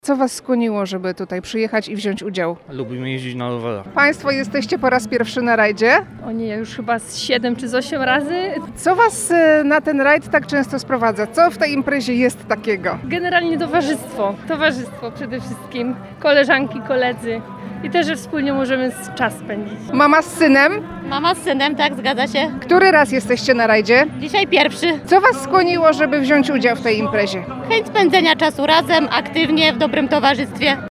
Od samych uczestników usłyszeliśmy to samo. Biorą udział w imprezie, bo lubią jeździć na rowerach i aktywnie spędzać czas z bliskimi lub przyjaciółmi.